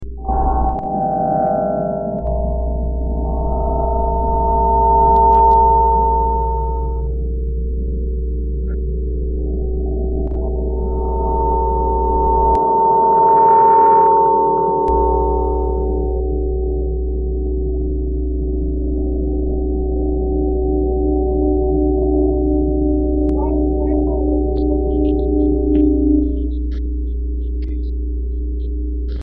tableharp.mp3